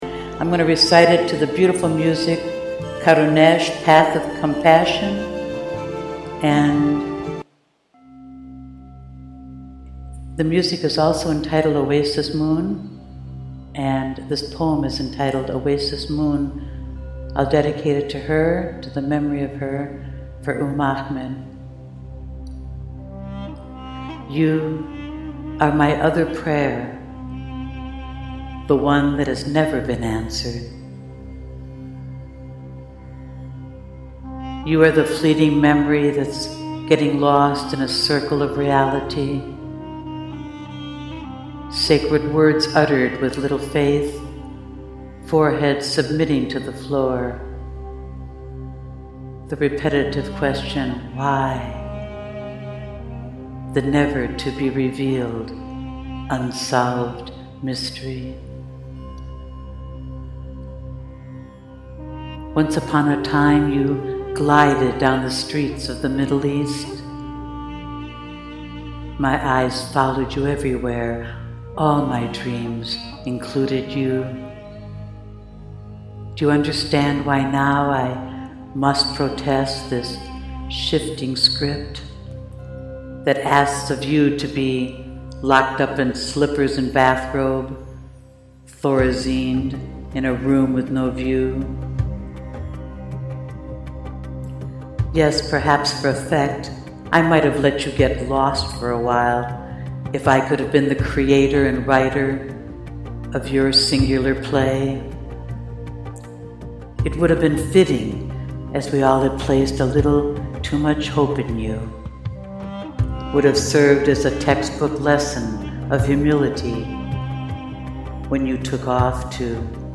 This is so beautifully soulful… 😍
oasis-moon-live.mp3